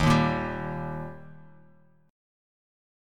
Eb Chord
Listen to Eb strummed